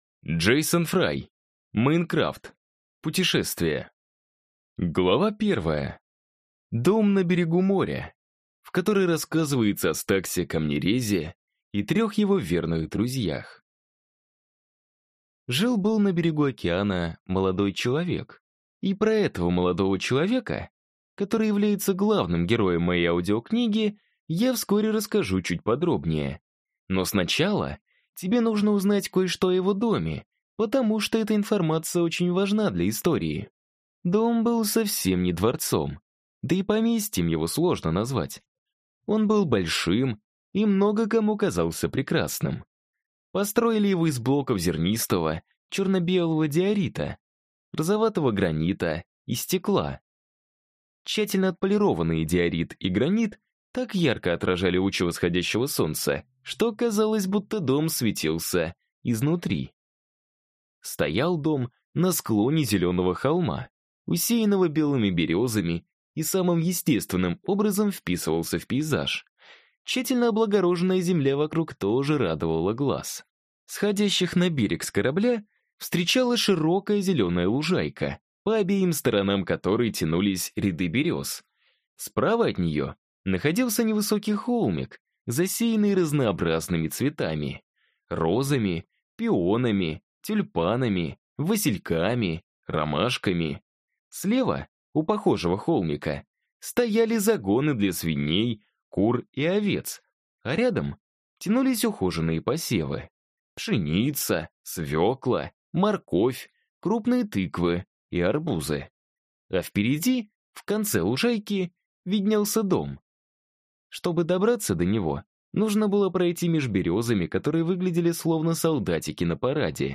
Аудиокнига Minecraft: Путешествие | Библиотека аудиокниг